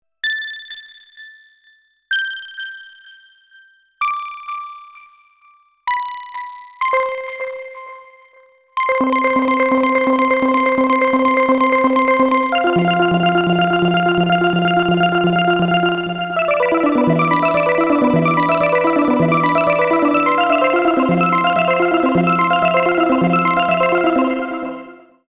クリスタル系のアルペジエイター音色。